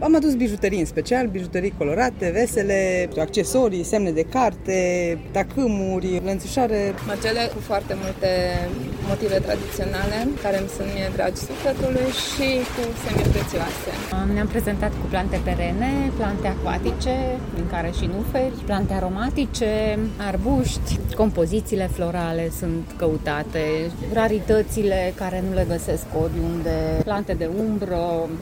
Cumpărătorii s-au orientat spre lucruri mici și suveniruri, iar meșterii populari spun că se simte tot mai mult criza financiară.